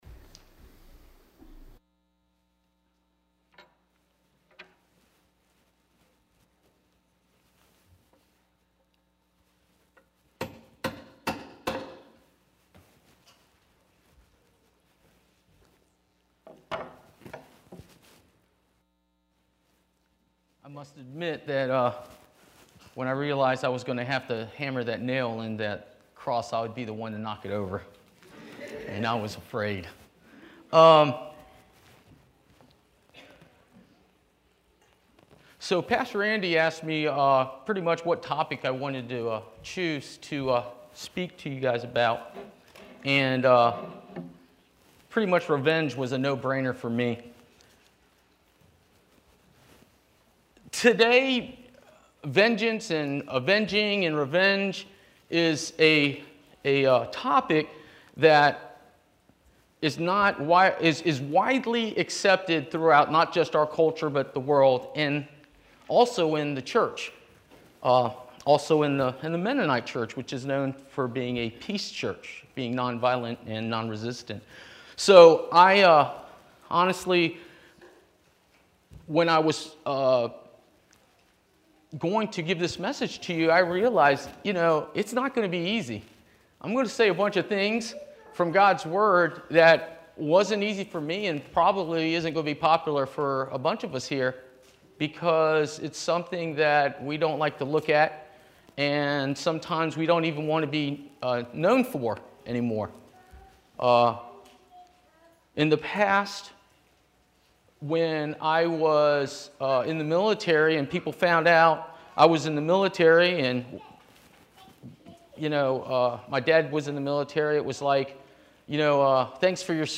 Service 9:30 am Worship